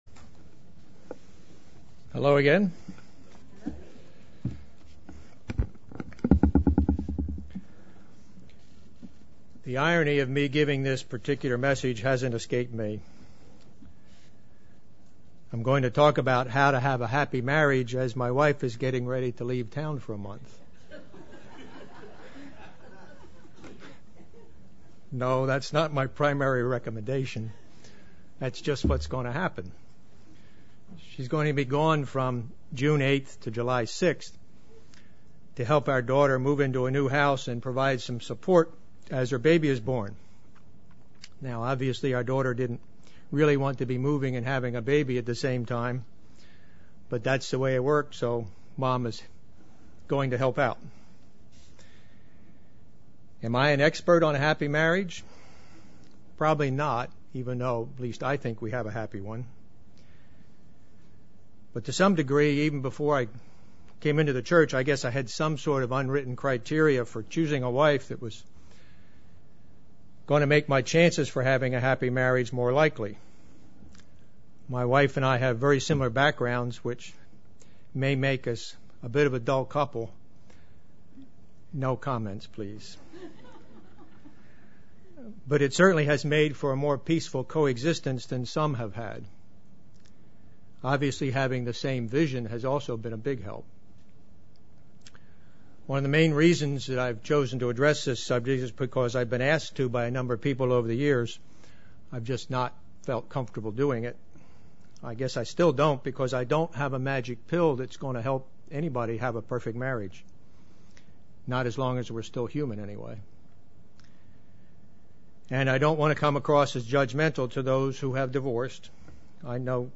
Given in Tampa, FL
Print To show the importance of marriage & how to improve likelihood of a successful one UCG Sermon Studying the bible?